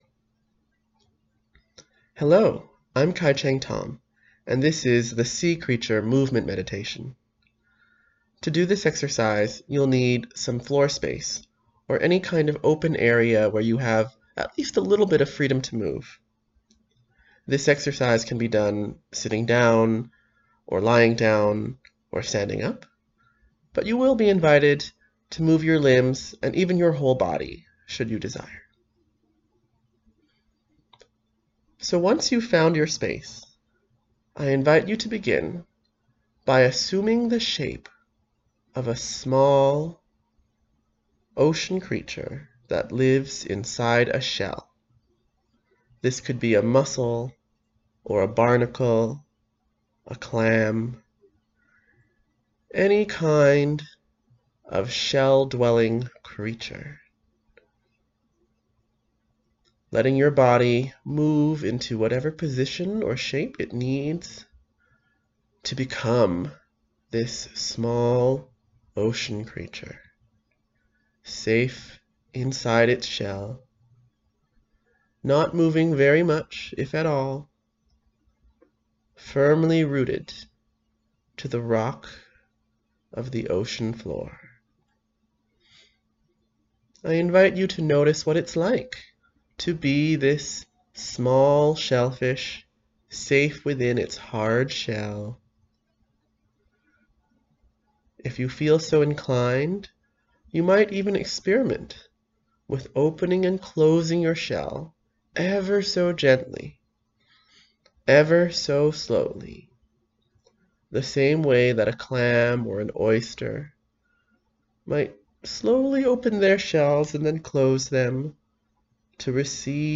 Sea creature movement meditation guided audio track